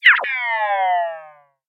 Это могут быть сигналы смартфонов, ноутбуков, планшетов и других гаджетов.
Звук разряженного аккумулятора в гаджете